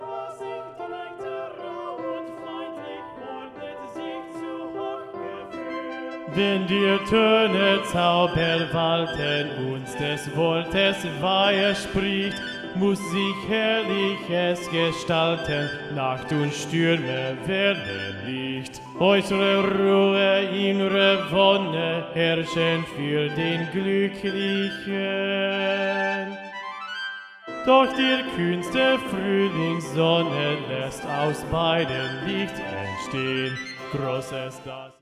Bass Vocal Model Recording (all Basses will sing the Bass, bottom note, not the Tenor 2, middle note)